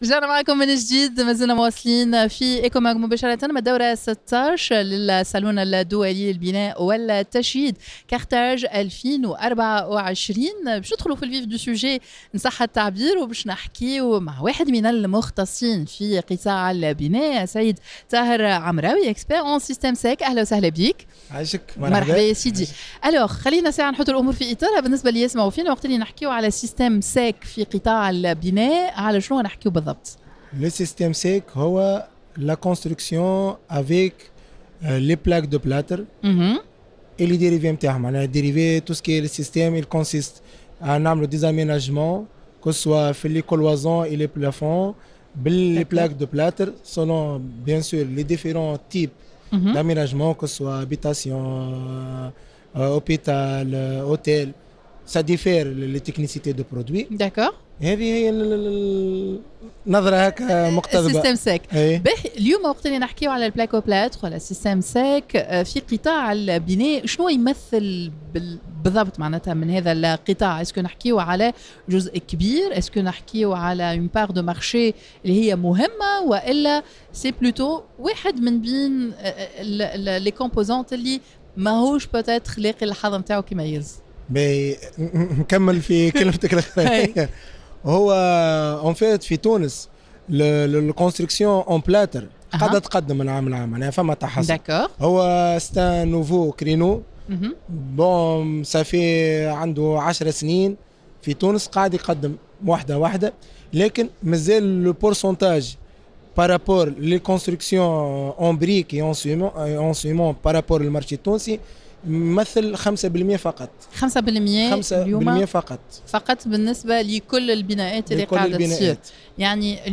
en direct dans un plateau spécial du foire El Kram